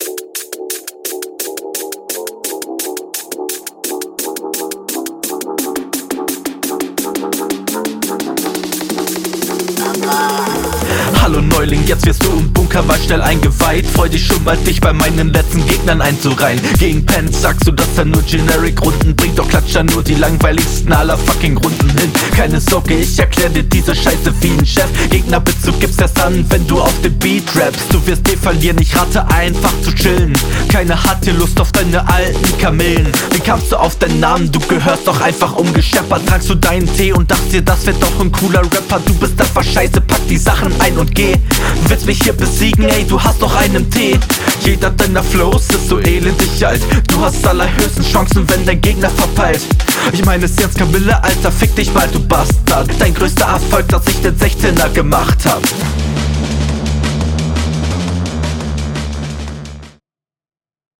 Beat geht ab. Soundquality chillig.
Richtig nicer Beat.
Der Flow kommt hier deutlich nicer und die Aussprache auch, was mich stört ist dass …